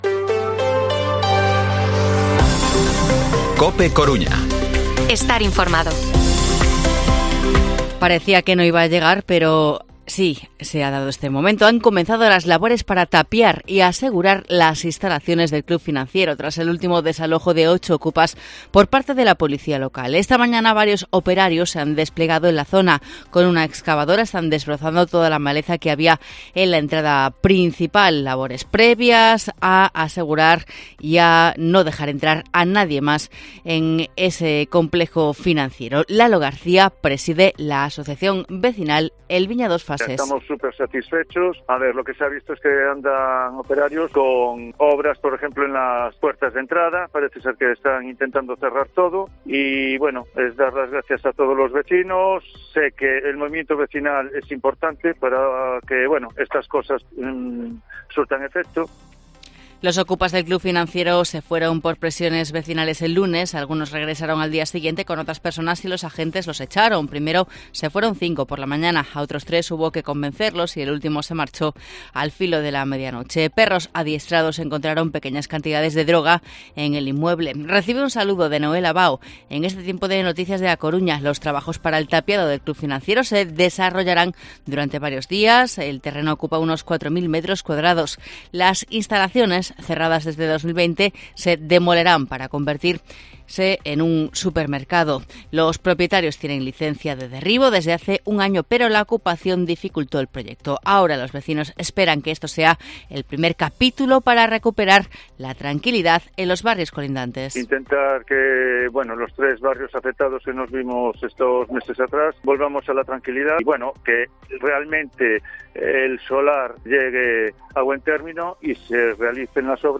Informativo Mediodía COPE Coruña viernes, 1 de septiembre de 2023 14:20-14:30